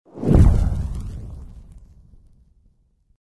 Звуки перехода, смены кадра
Летящий огненный шар с шумом пламени для перехода